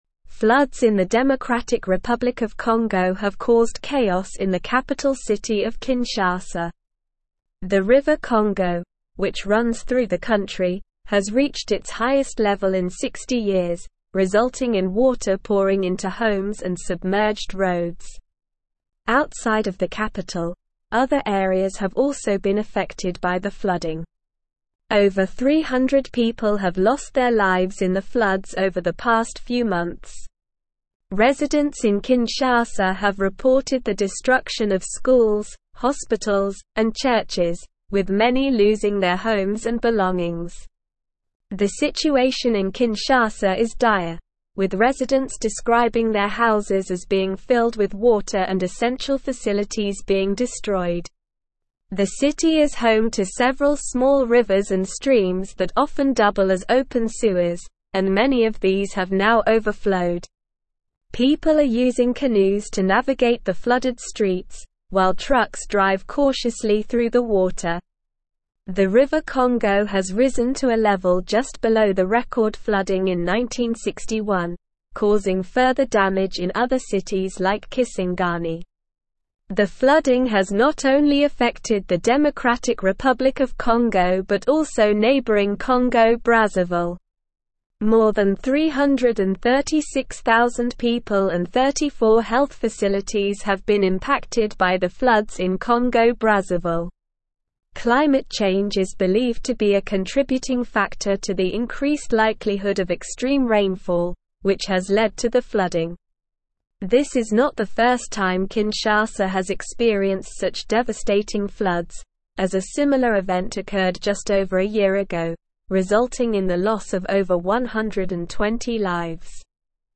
Slow
English-Newsroom-Advanced-SLOW-Reading-Congos-Capital-Kinshasa-Devastated-by-Record-Floods.mp3